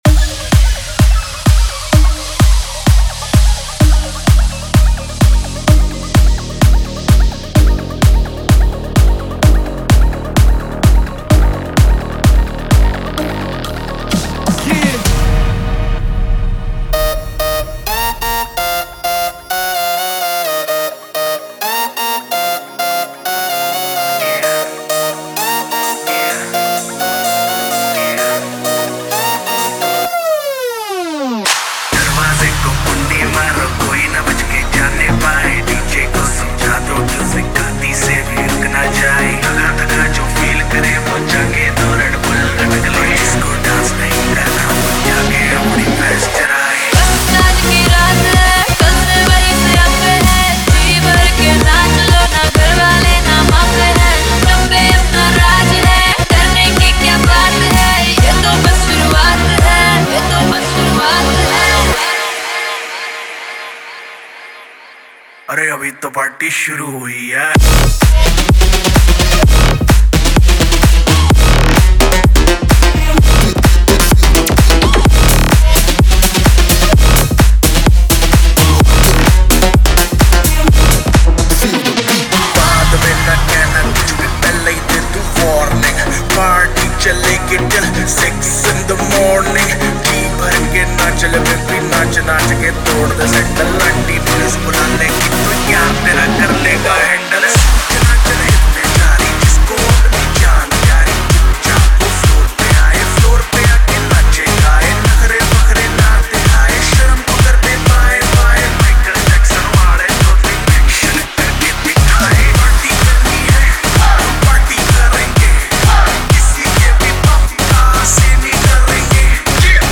EDM Remix | Dance Remix Song
Dholki Remix Mp3 Song Free
Category: Latest Dj Remix Song